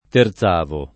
terzavolo [ ter Z# volo ]